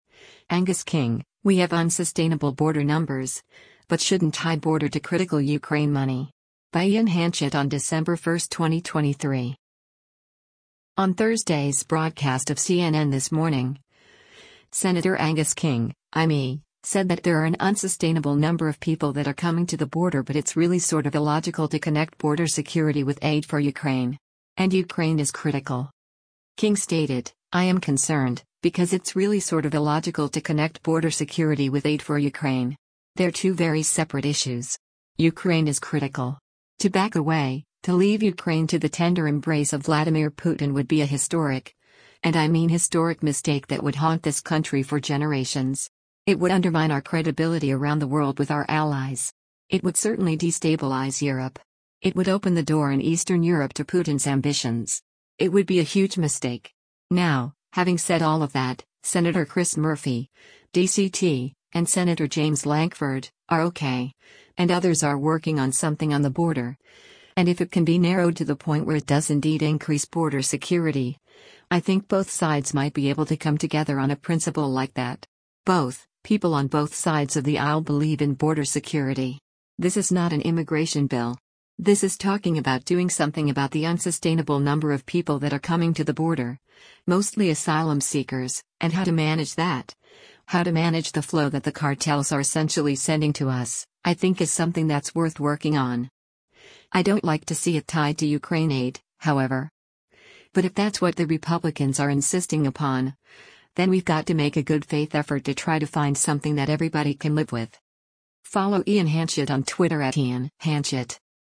On Thursday’s broadcast of “CNN This Morning,” Sen. Angus King (I-ME) said that there are an “unsustainable number of people that are coming to the border” but “it’s really sort of illogical to connect border security with aid for Ukraine.” And “Ukraine is critical.”